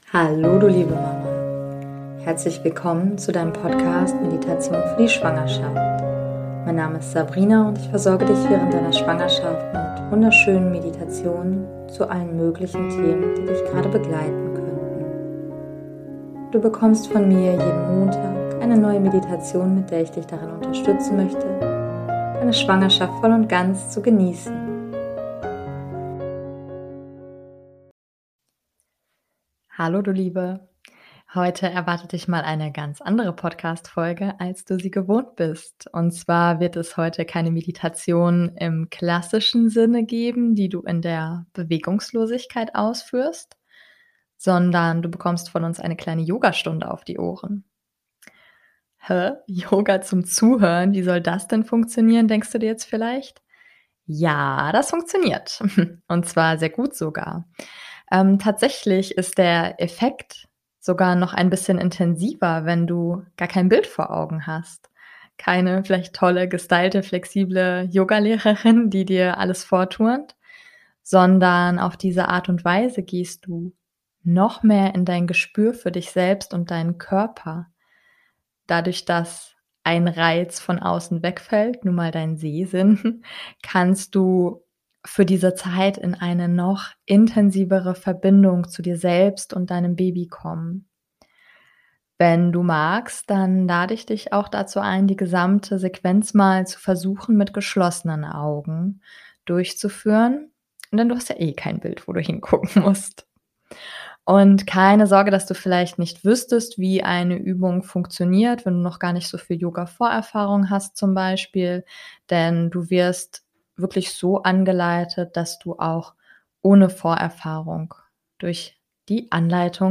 Und zwar wird es heute keine Meditation geben, die du in der Bewegungslosigkeit ausführst, sondern du bekommst von uns eine kleine Yogastunde auf die Ohren.